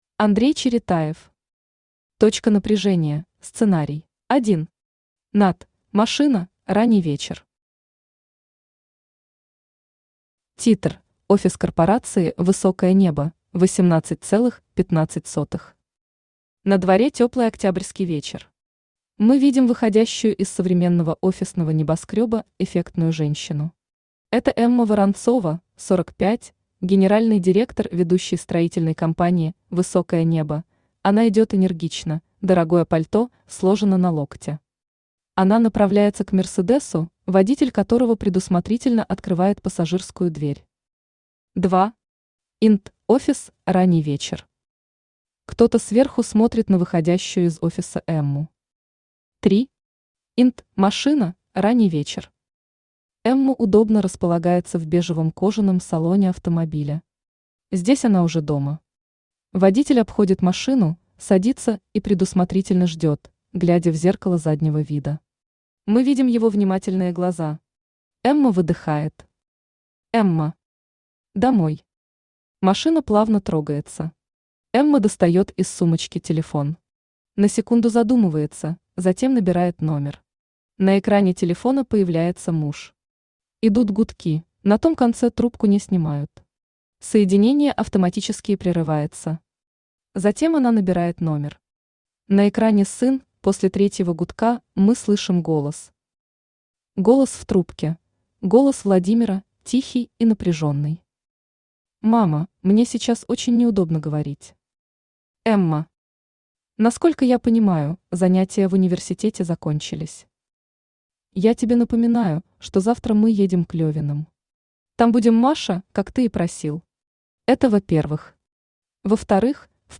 Аудиокнига Точка напряжения (сценарий) | Библиотека аудиокниг
Aудиокнига Точка напряжения (сценарий) Автор Андрей Александрович Черетаев Читает аудиокнигу Авточтец ЛитРес.